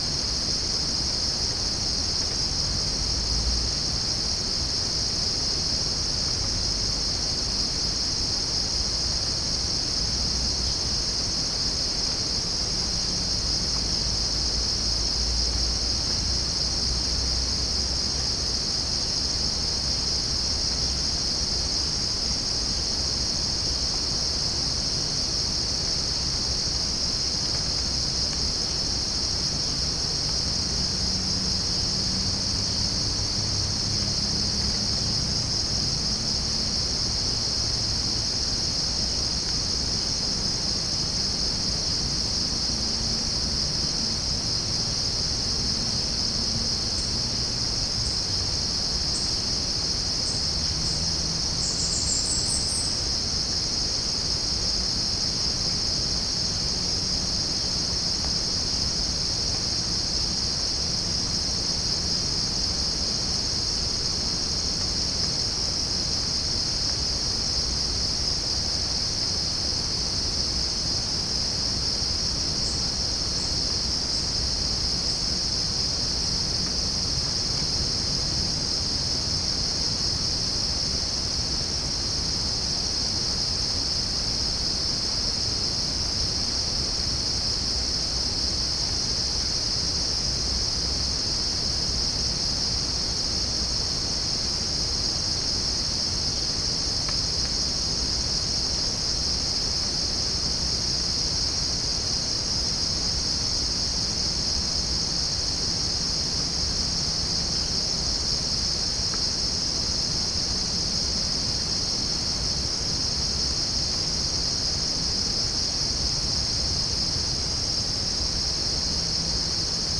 Chalcophaps indica
Pycnonotus goiavier
Halcyon smyrnensis
Pycnonotus aurigaster
Orthotomus ruficeps
Dicaeum trigonostigma